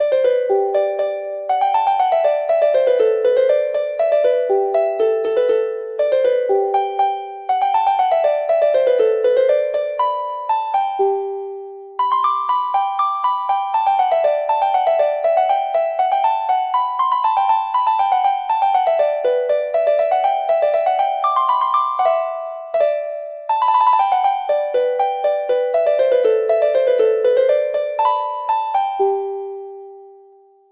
Traditional
Harp version